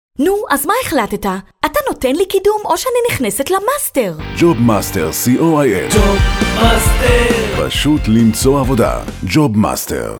דוגמאות קריינות לפרסומות – אאודיו